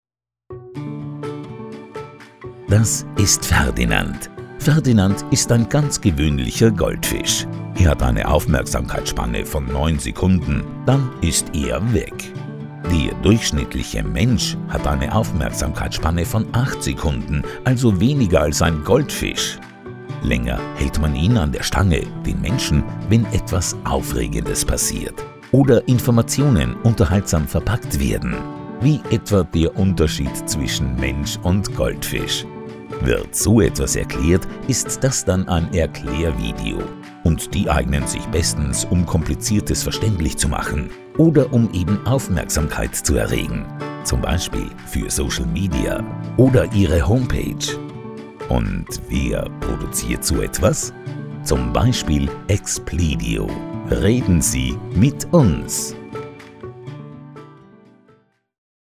Male
Freundliche tiefe Männerstimme, akzentfrei und hochdeutsch.
Explainer Videos